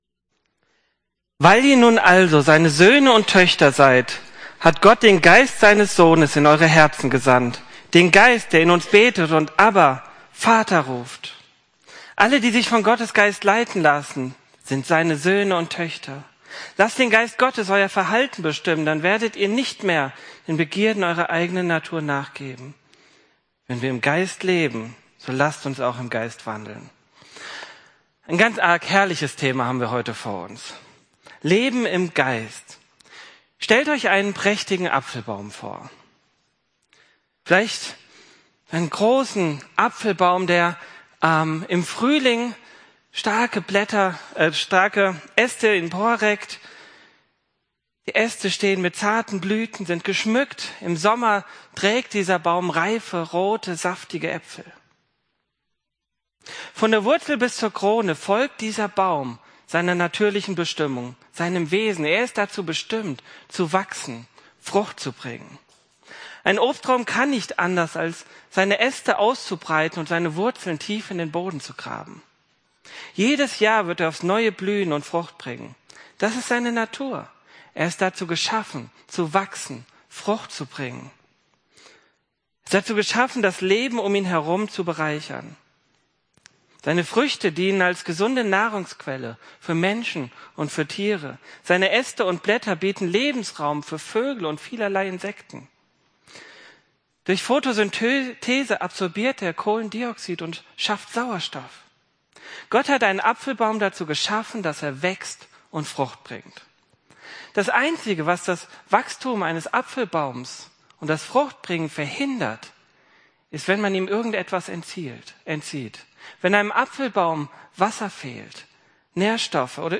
Predigt und Vorbereitungshilfe #2: Freiheit im Geist